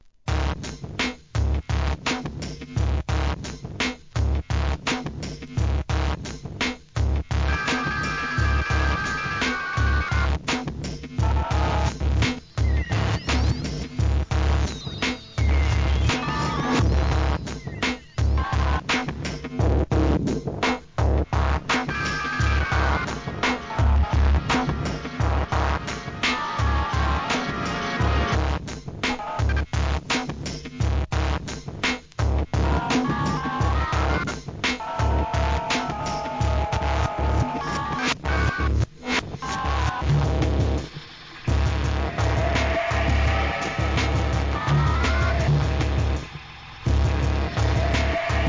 Abstract,ブレイクビーツ!